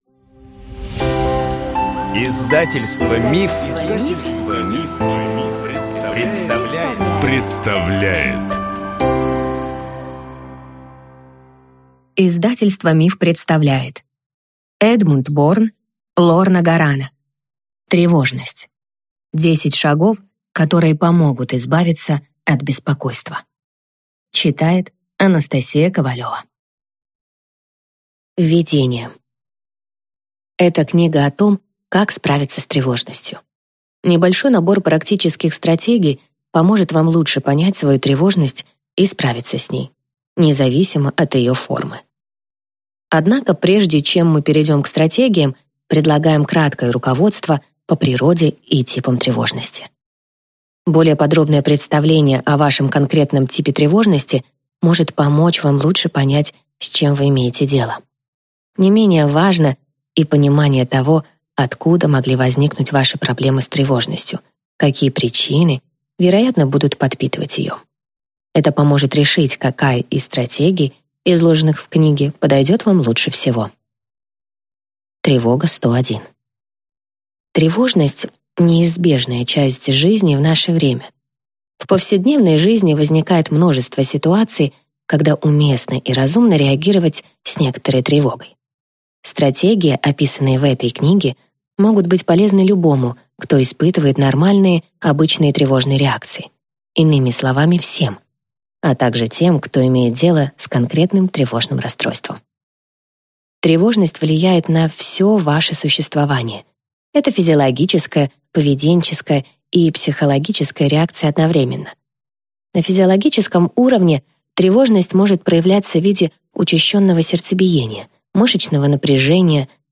Аудиокнига Тревожность. 10 шагов, которые помогут избавиться от беспокойства | Библиотека аудиокниг